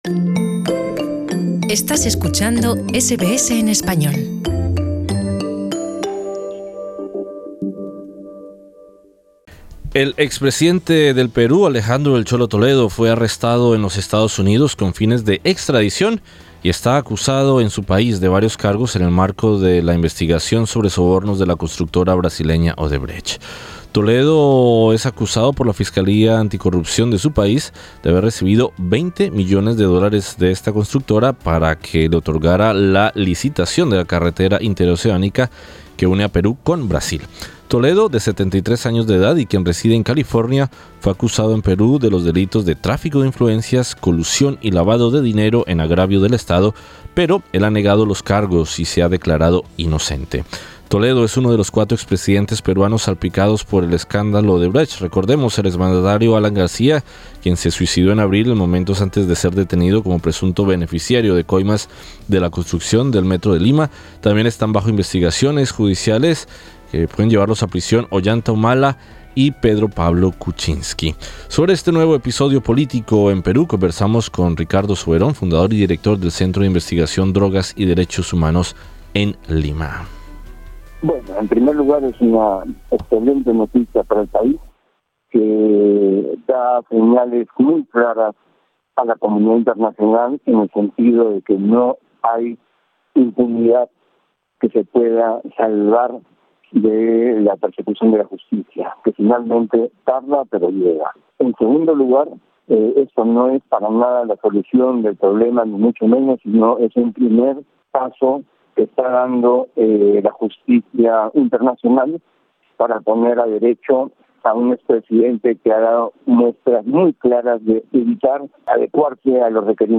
En entrevista con Radio SBS